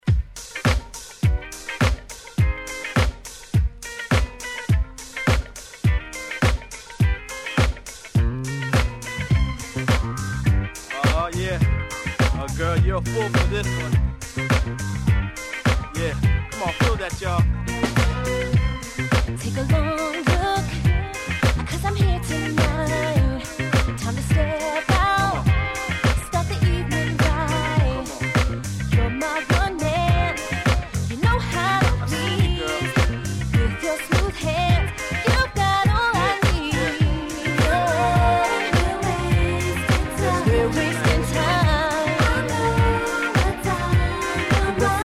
ダンクラ調で展開が非常に気持ちの良い
※試聴ファイルはWhite盤から録音したものです。